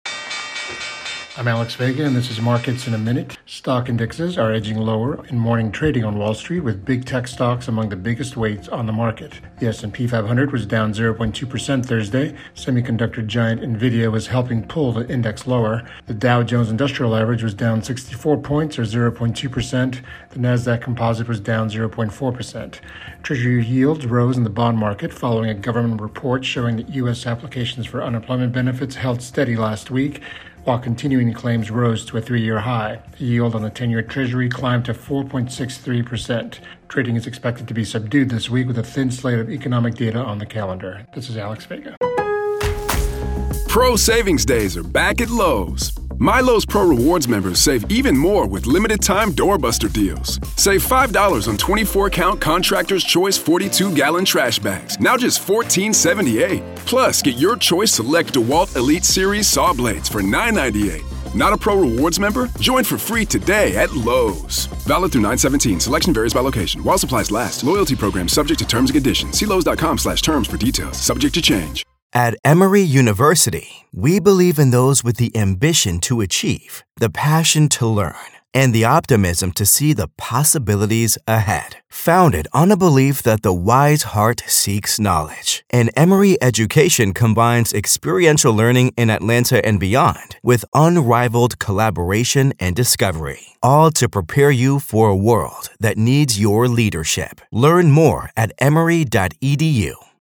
AP Markets in a Minute report